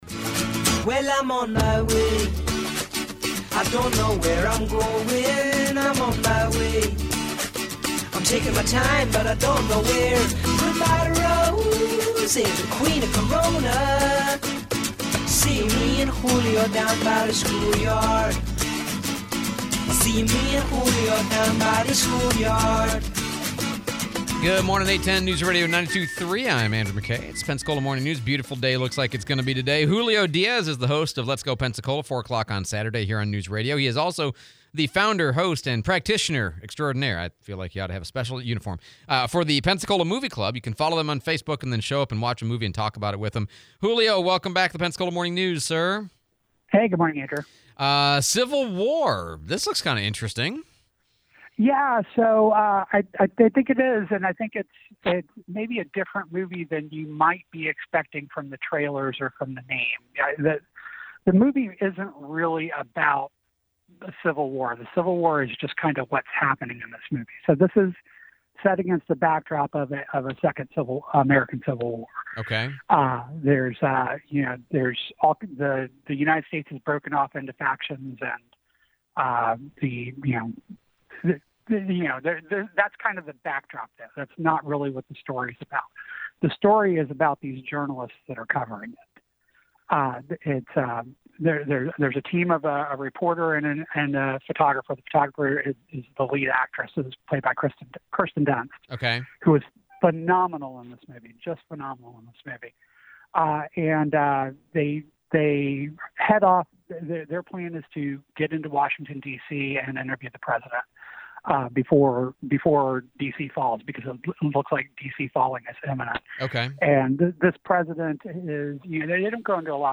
04/12/2024 Interview